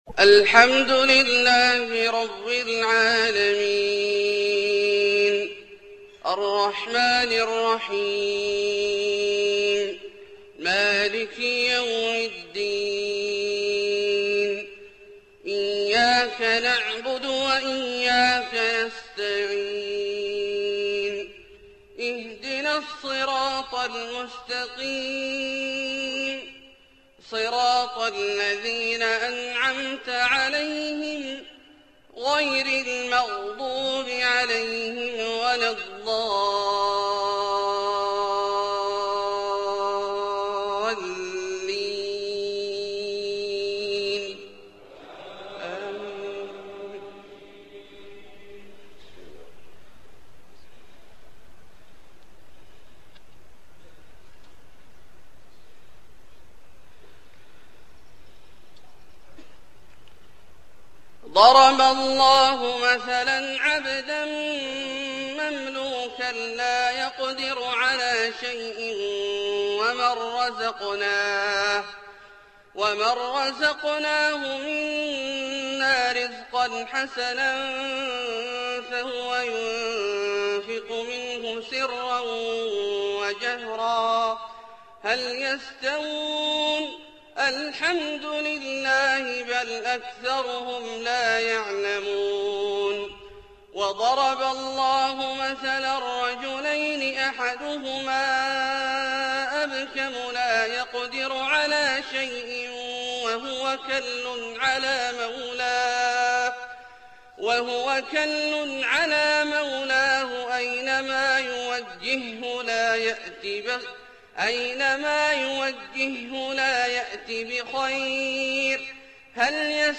فجر 9-8-1428 من سورة النحل {75-89} > ١٤٢٨ هـ > الفروض - تلاوات عبدالله الجهني